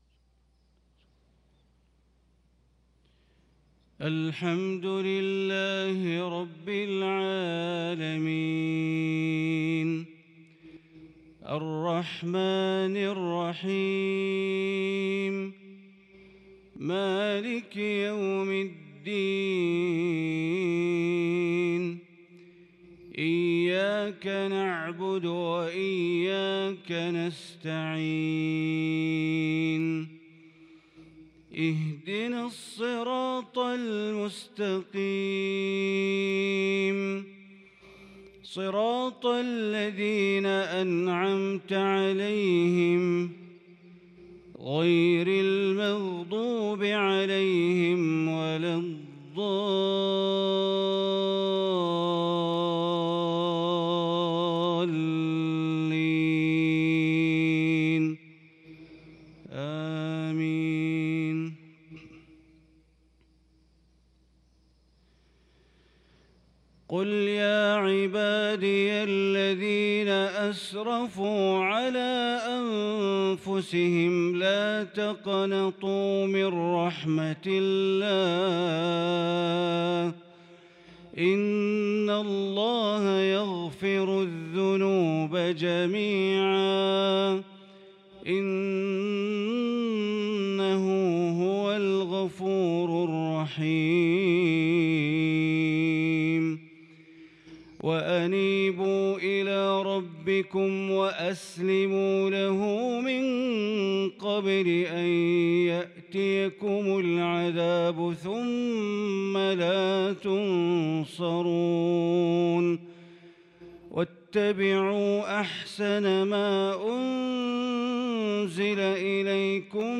صلاة الفجر للقارئ بندر بليلة 25 ربيع الأول 1443 هـ
تِلَاوَات الْحَرَمَيْن .